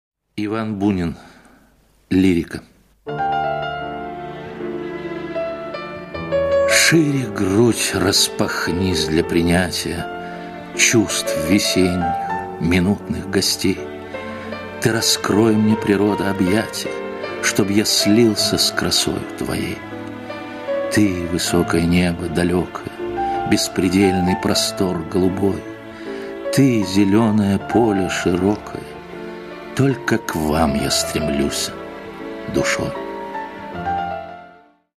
1. «Бунин И.А. – Шире грудь распахнись для принятия [читает Виктор Зозулин]» /
I.A.-SHire-grud-raspahnis-dlya-prinyatiya-chitaet-Viktor-Zozulin-stih-club-ru.mp3